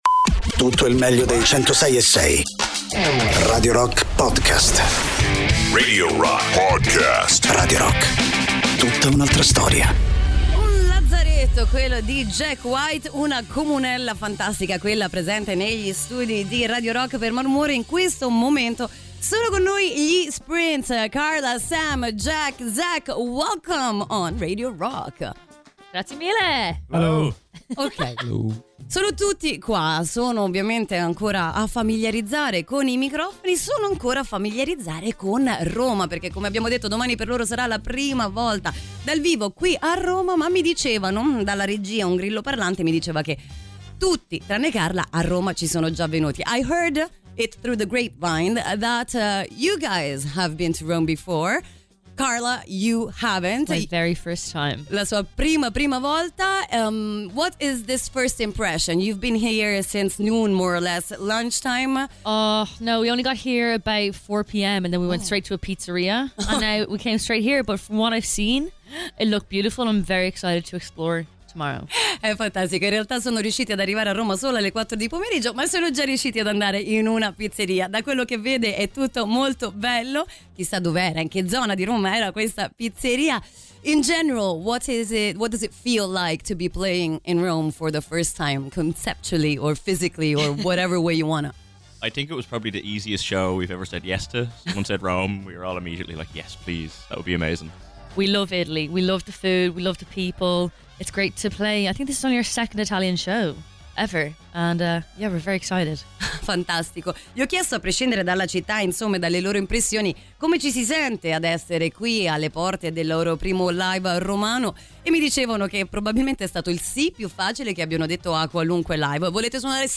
Intervista: SPRINTS (19-09-25)